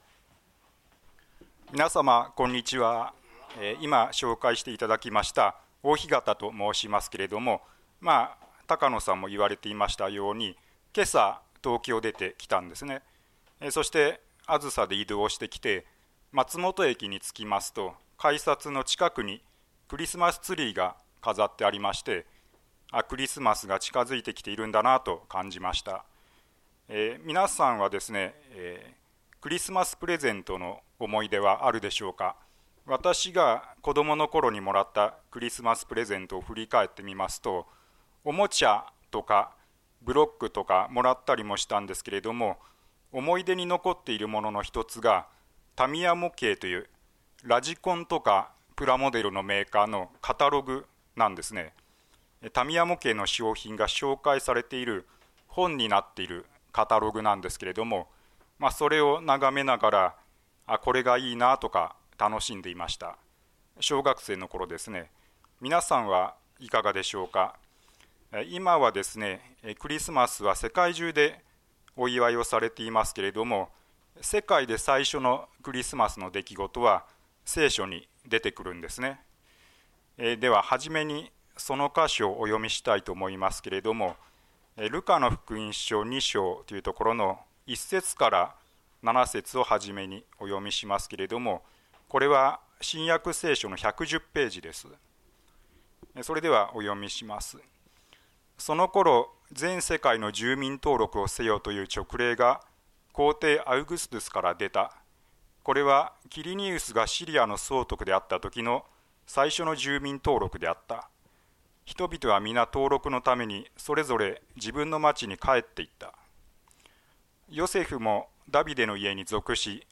聖書メッセージ No.299